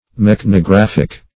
Search Result for " mechanographic" : The Collaborative International Dictionary of English v.0.48: Mechanographic \Mech`an*o*graph`ic\ (m[e^]k`an*[-o]*gr[a^]f"[i^]k), a. 1.
mechanographic.mp3